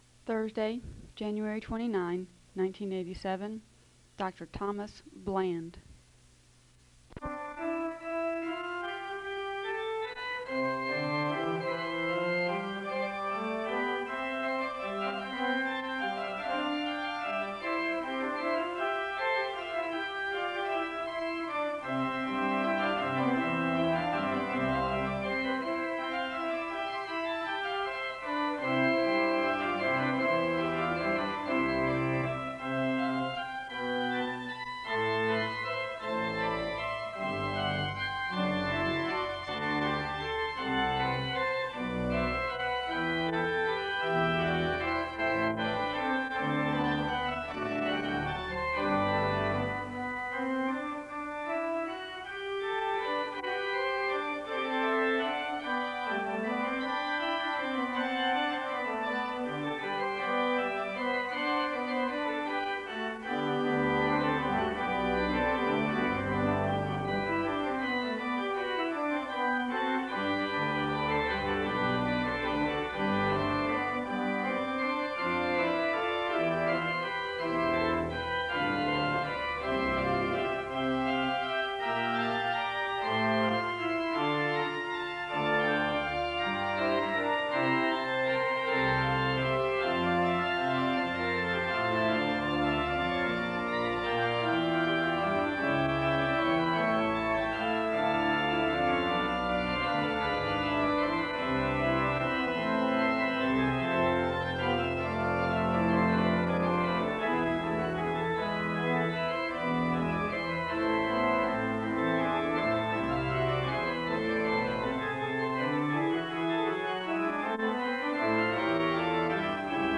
The service begins with organ music (0:00-5:18). There is a Scripture reading and a moment of prayer (5:19-7:56). The choir sings a song of worship (7:57-10:08). There is a Scripture reading from Philippians 1 and context is given for the passage (10:09-12:00).
There is a benediction to conclude the service (27:52-28:25).